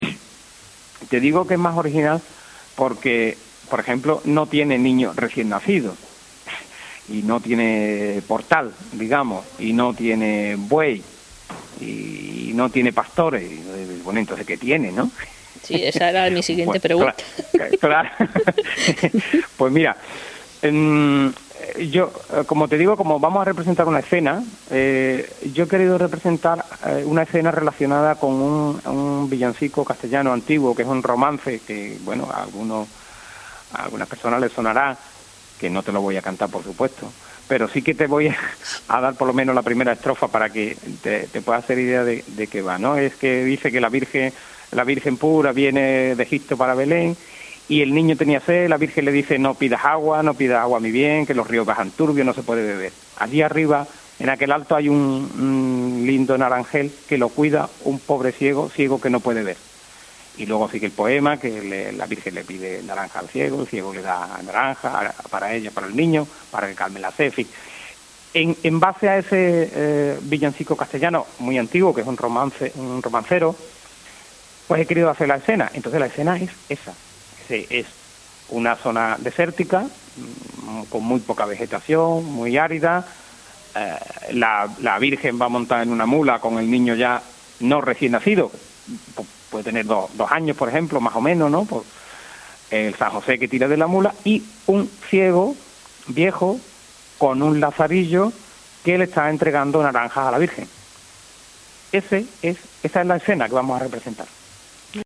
Un villancico con origen en el castellano antiguo complementa la escena del Belén